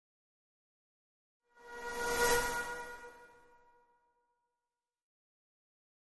Static Buzz By Thin Buzz, Creepy Pass - Version 4